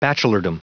Prononciation du mot bachelordom en anglais (fichier audio)
Prononciation du mot : bachelordom